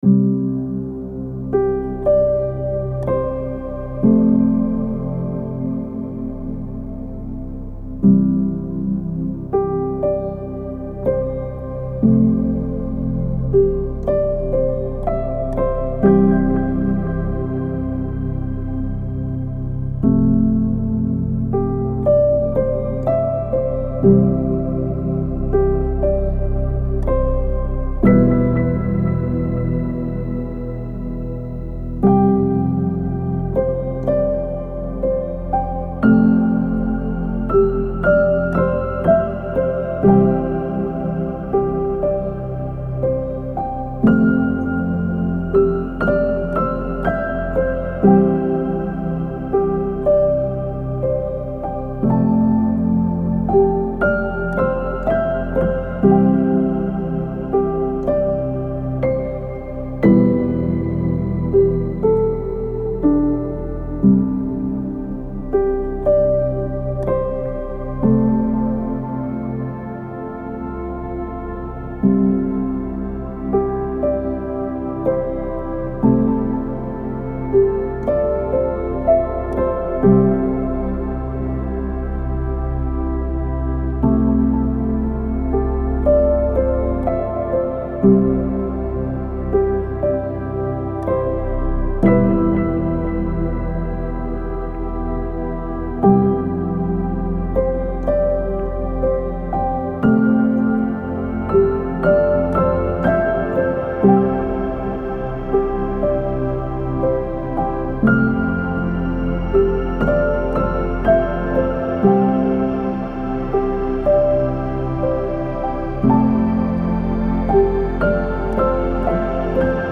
موسیقی کنار تو
آرامش بخش , پیانو , عصر جدید , موسیقی بی کلام
پیانو آرامبخش موسیقی بی کلام نیو ایج